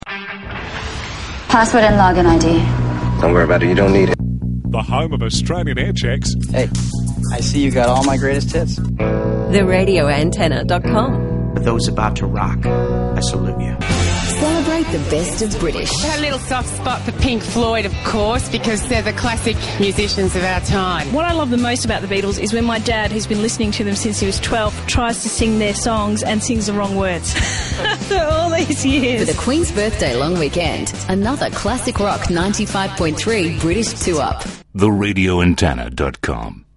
RA Aircheck -Classic Rock 95.3 Queens Birthday 2 up 2010